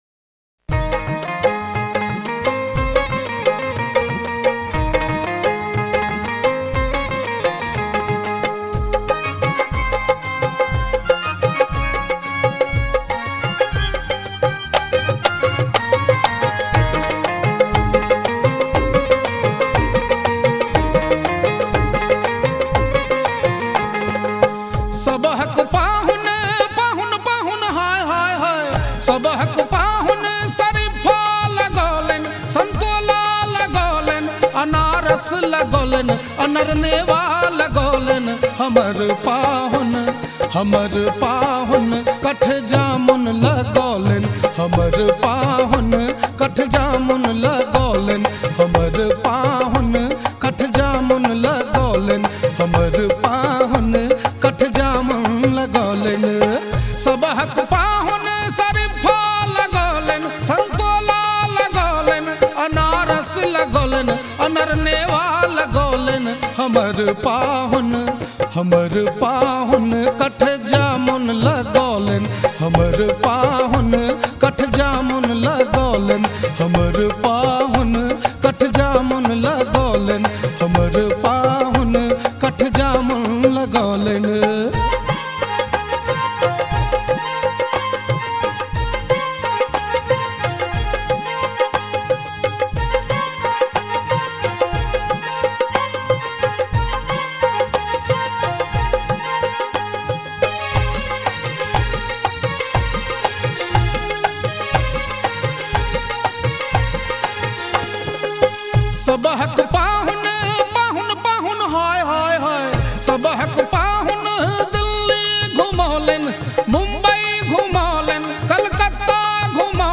Maithili Lokgeet